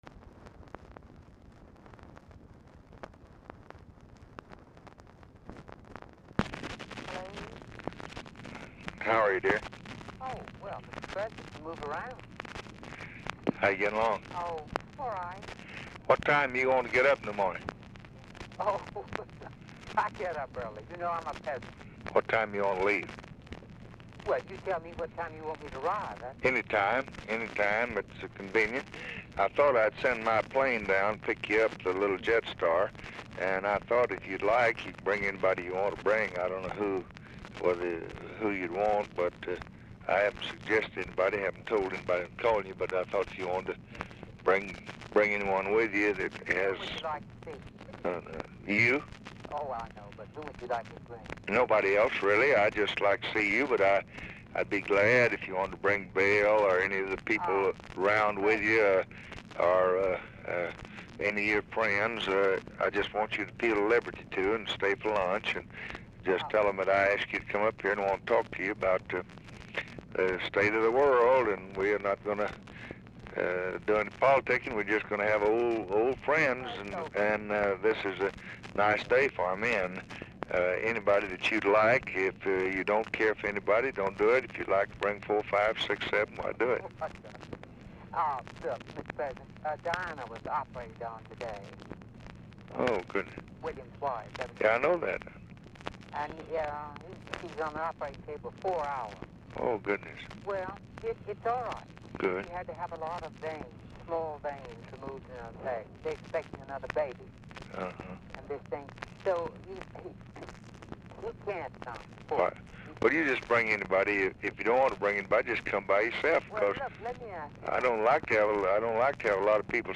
LBJ Ranch, near Stonewall, Texas
Telephone conversation
Dictation belt